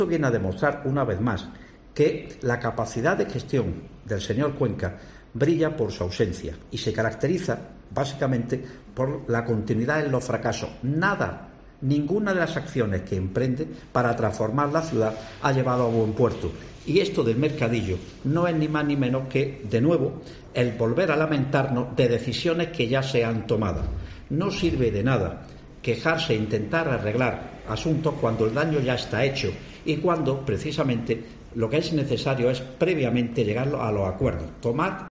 Luis González, portavoz del PP en el Ayuntamiento de Granada